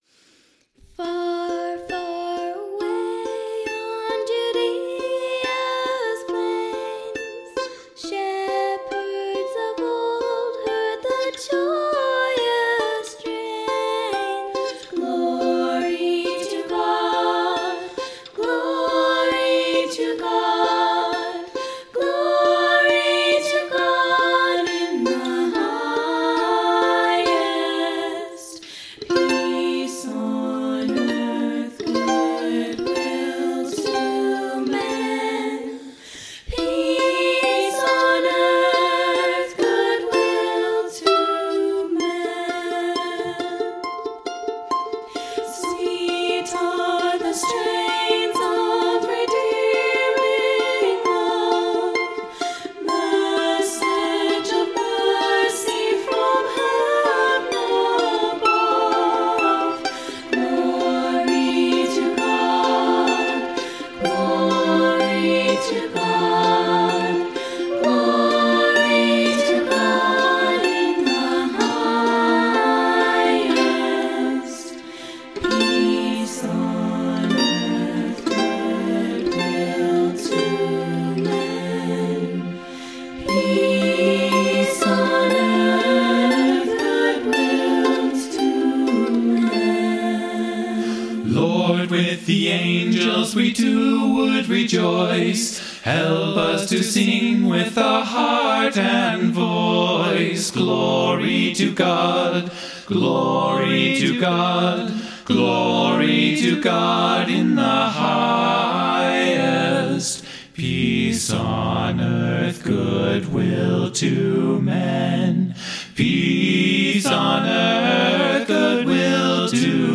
Everyone in the family except the youngest child participated in this year’s Christmas recording.
I played the guitars and mandolin. A western frontier song really needed an arrangement with guitars and mandolin.
The entire production was done in Ardour 5.12 running on Linux Mint 19.3. Plugins included Dragonfly Reverb, compressors and limiters from Linux Studio Plugins, 5-band EQ from Calf Studio Gear, and the Virtual Playing Orchestra running in sfizz.